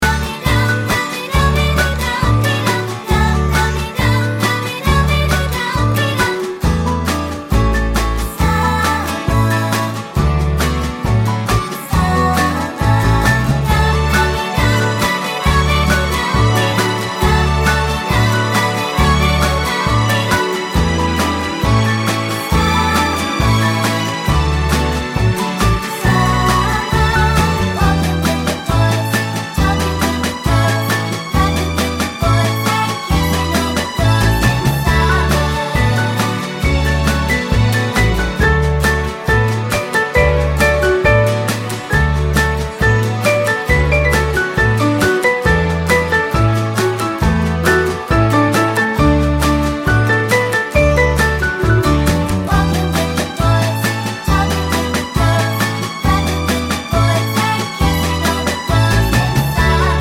One Semitone Up Pop (1960s) 2:52 Buy £1.50